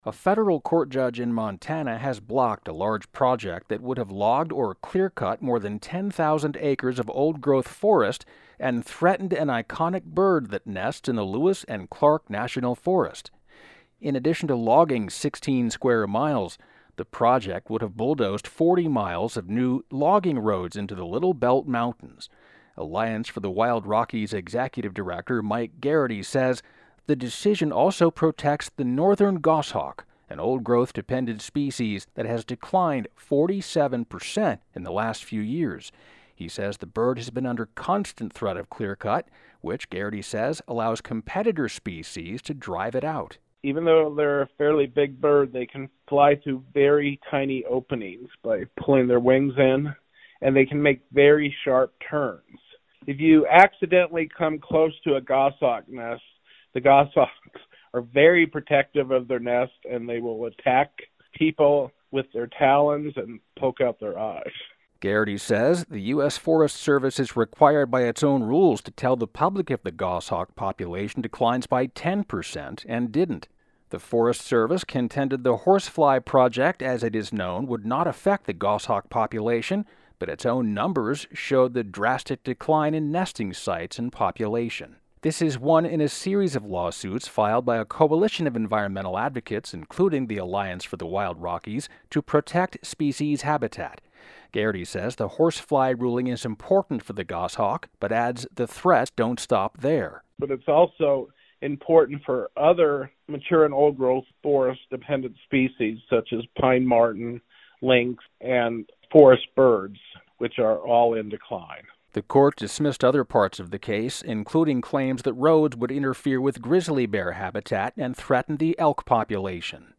Big Sky Connection - A federal judge in Montana has shut down a 10,000-acre logging project in the Lewis and Clark National Forest, protecting habitat of the old growth forest-dependent goshawk (GOSS-hawk).